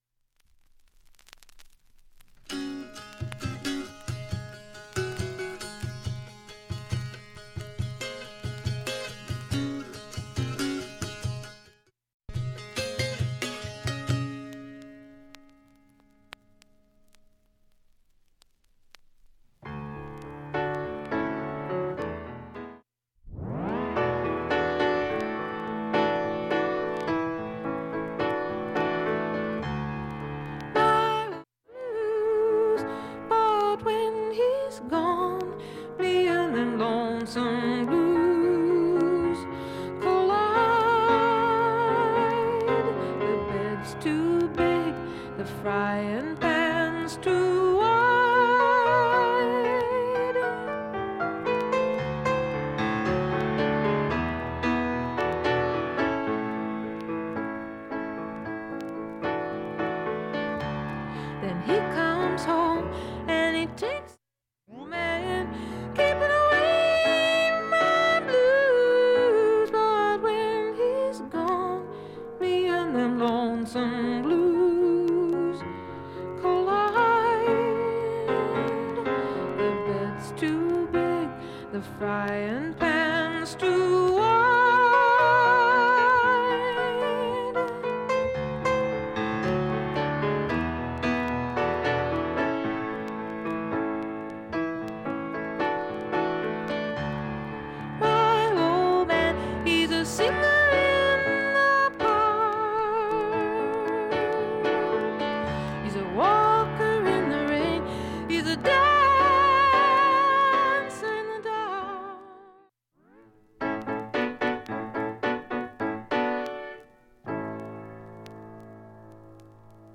音質良好全曲試聴済み。
単発のかすかなプツが５箇所